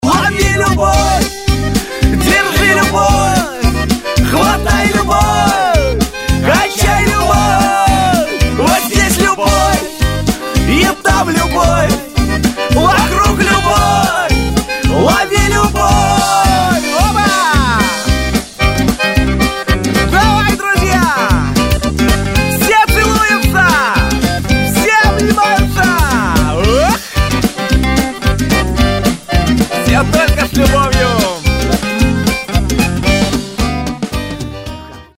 • Качество: 128, Stereo
веселые
Очень весело поют о любви